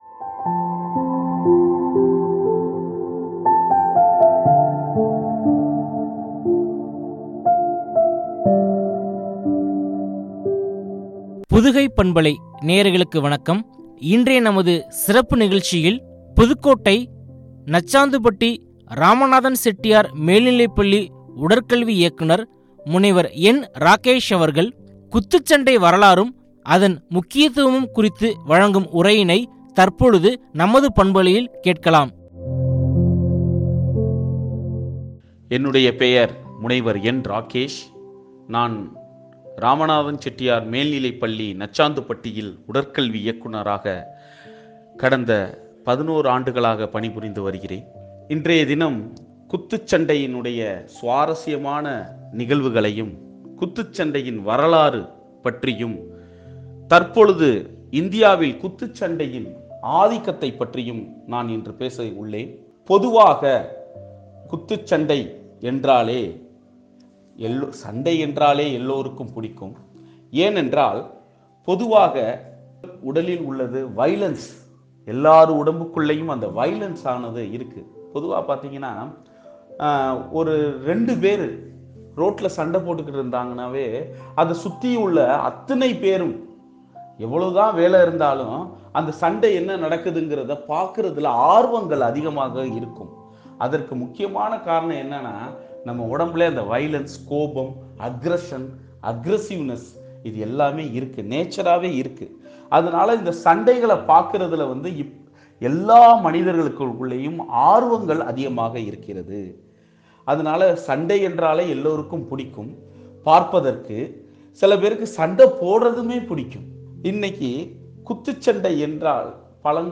” குத்துச்சண்டை வரலாறும், முக்கியத்துவம் ” குறித்து வழங்கிய உரையாடல்.